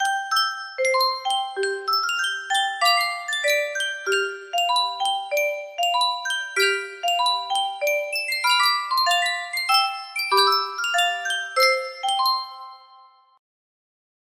Sankyo Caja de Musica - La Paloma CD music box melody
Full range 60